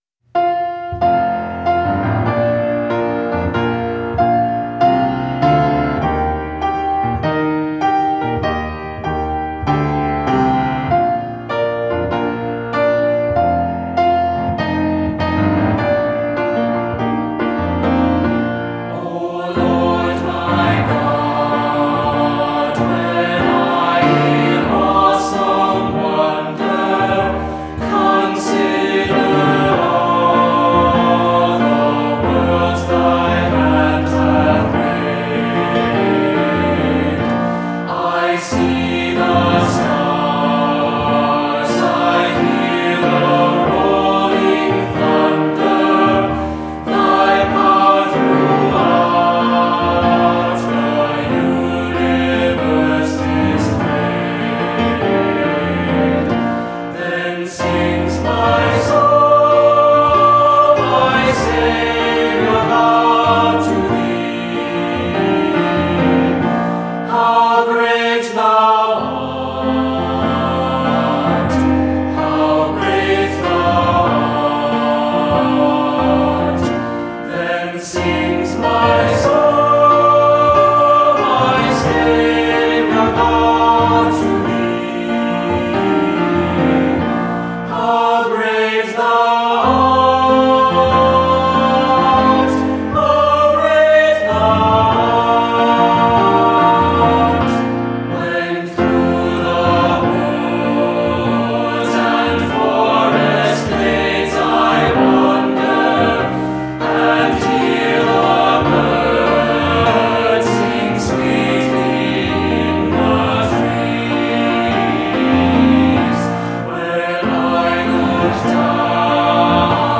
Voicing: SATB; Descant